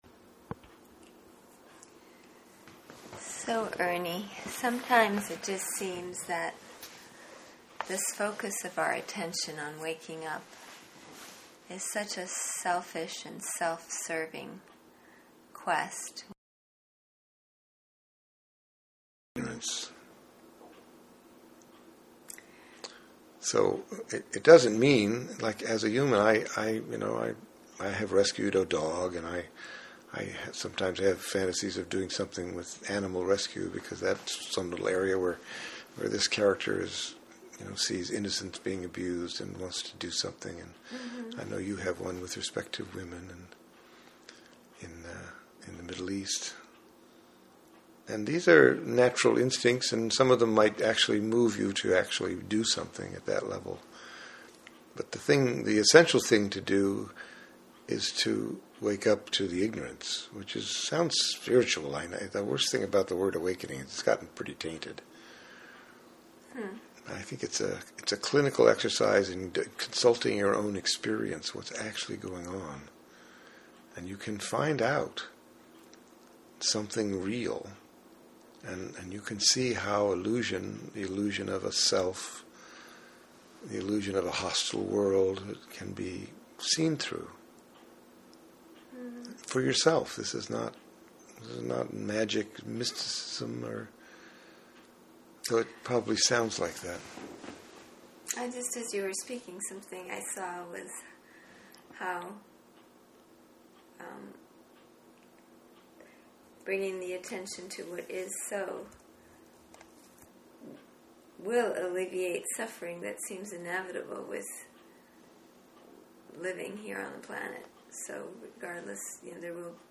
interview/discussion